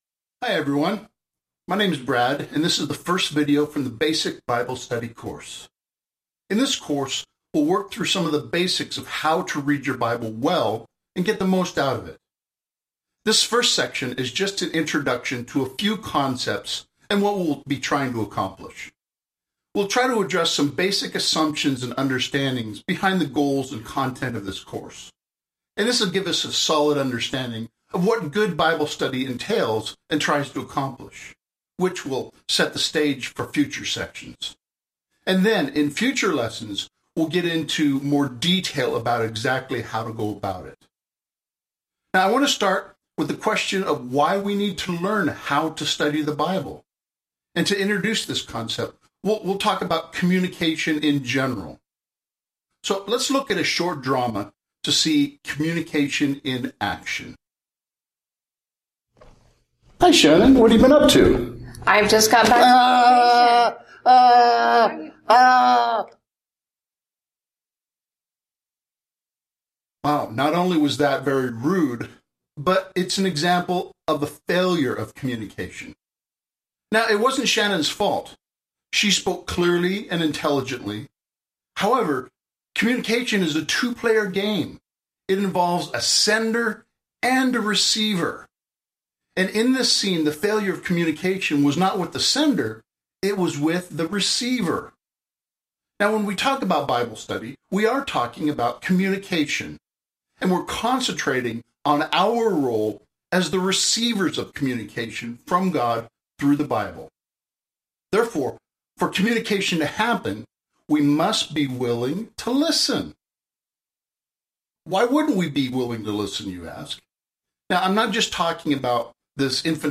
Videos In this section, you will find the video lectures for all the introductory course material module.